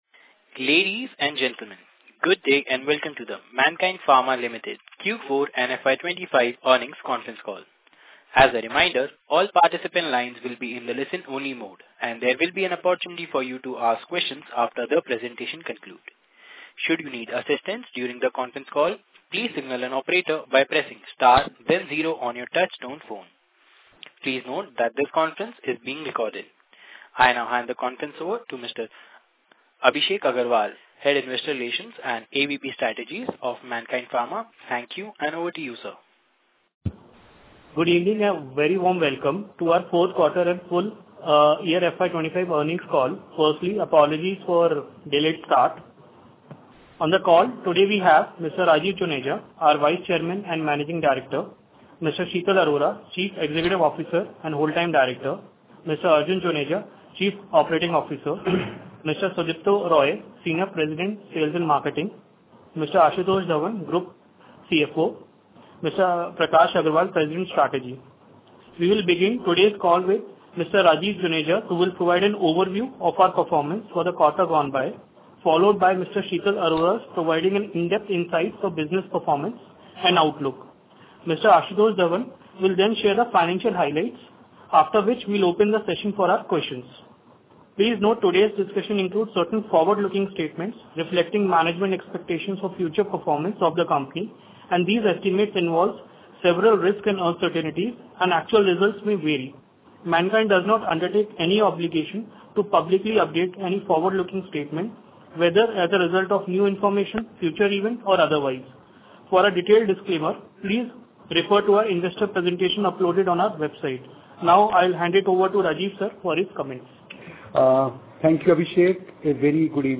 Q4FY25-Earnings-Call-Recording.mp3